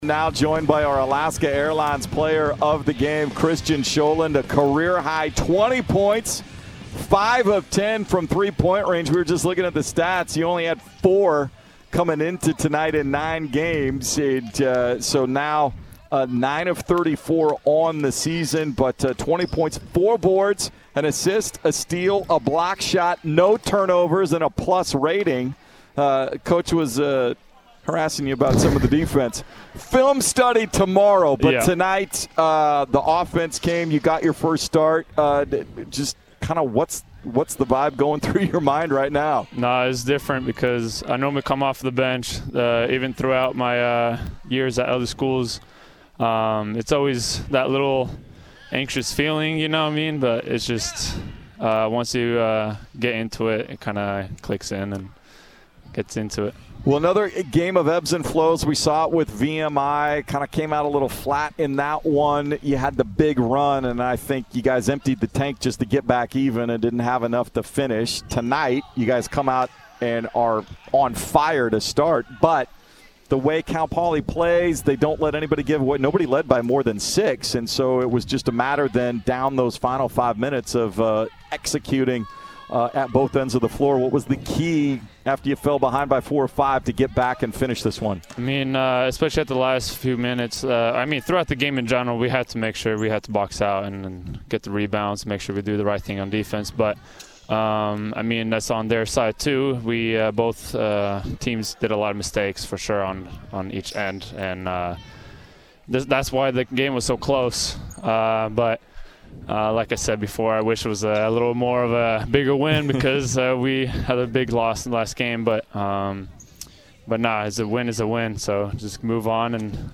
post-game interview